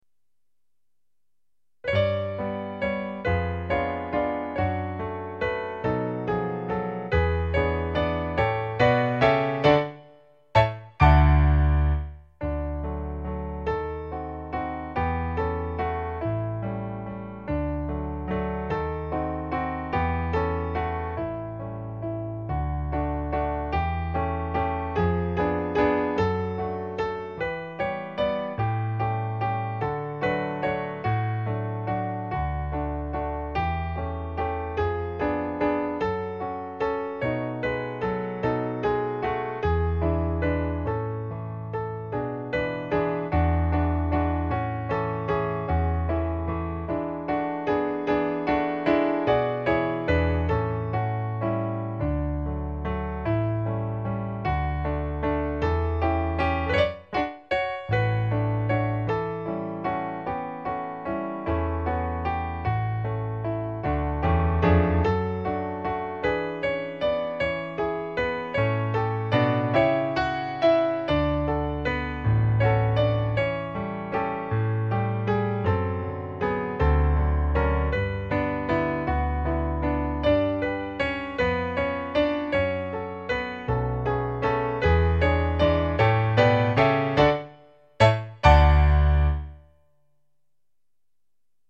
Time Signature: 3/4
Key: D
Notations: Tempo di valse